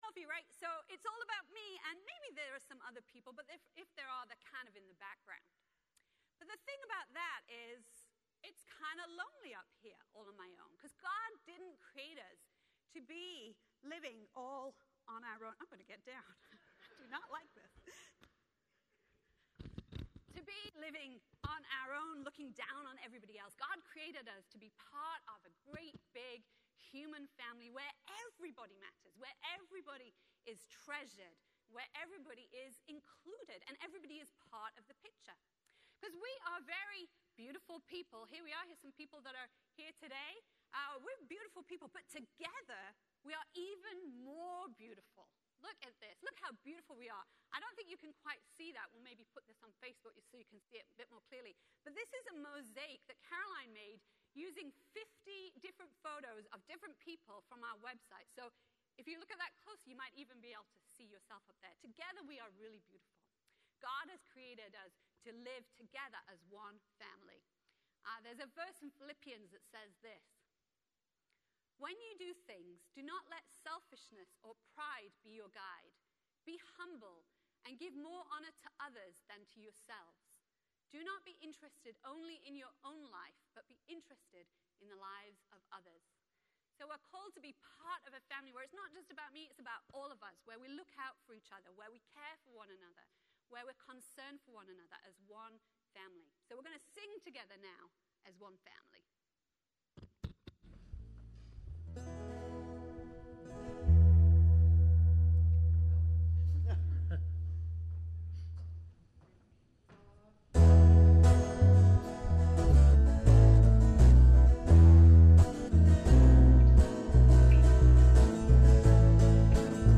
Message
During today's service for all ages, we explore how we're all connected to one another and to God. And God is with us even when we don't notice.